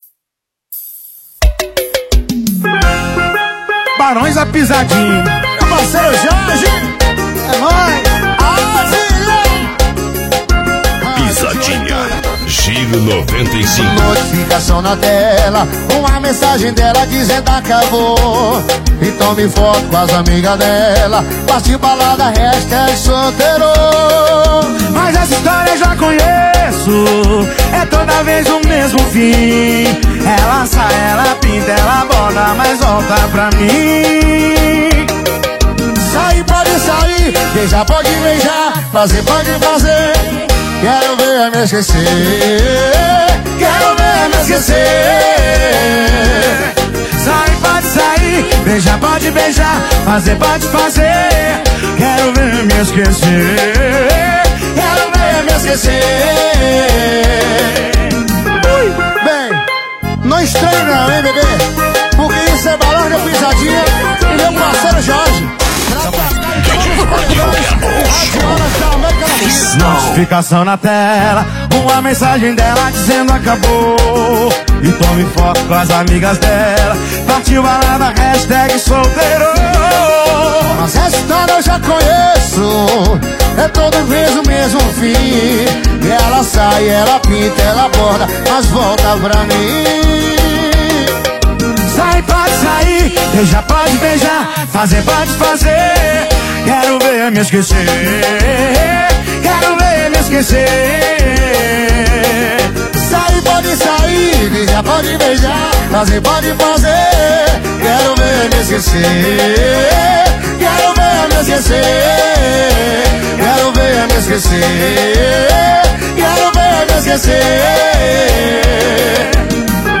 Seu fim de semana vai der Pisadinha sim!